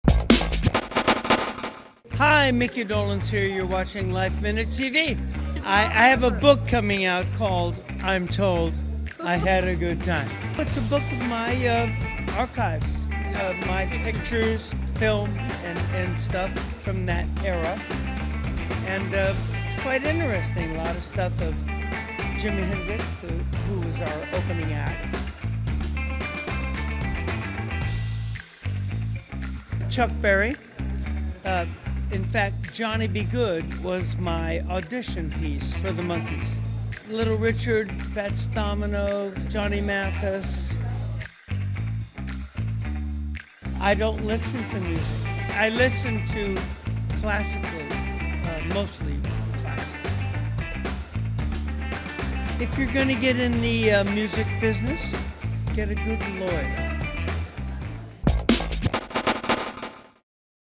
We caught up with him at the premiere of Inside in New York City. The Monkees sensation filled us in on his new book I’m Told I Had A Good Time: The Micky Dolenz Archives, Volume One.